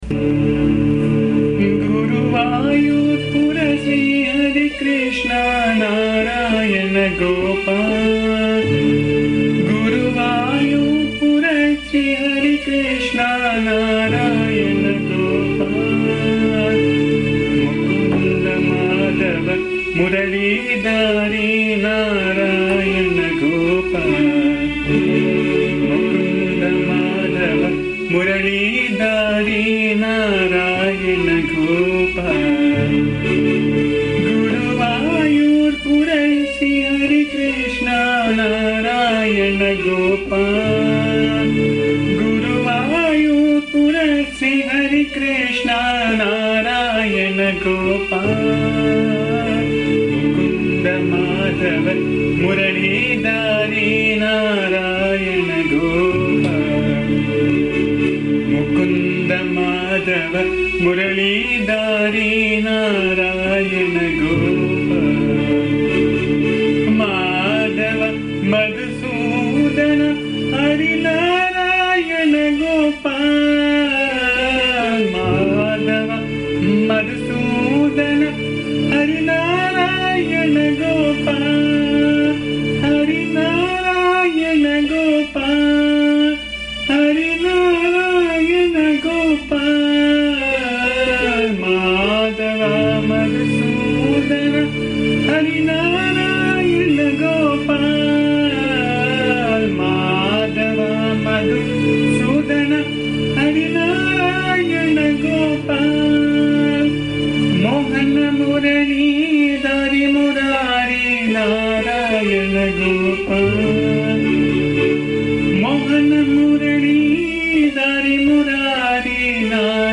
This song is a small beautiful bhajan praising Lord Krishna as situated in the town of guruvayoor. The words are simple with names of Krishna.
The song has been recorded in my voice which can be found here. Please bear the noise, disturbance and awful singing as am not a singer.
AMMA's bhajan song